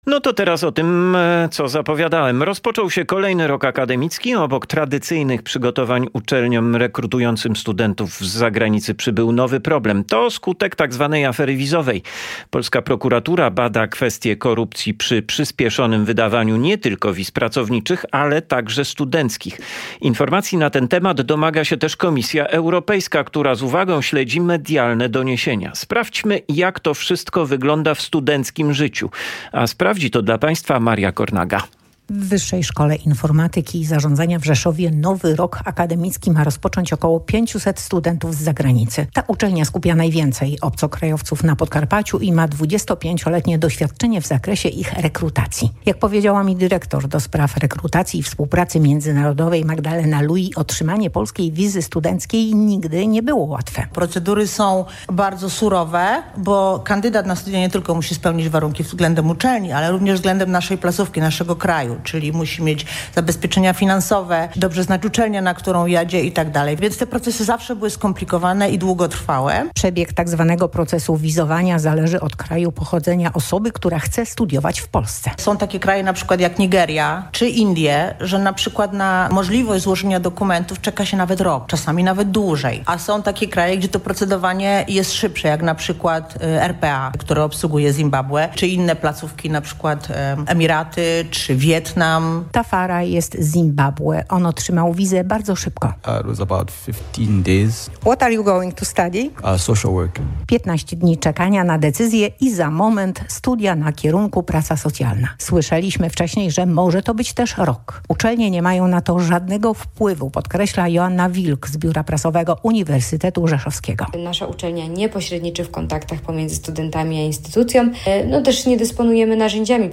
O wizach dla zagranicznych studentów – rozmowa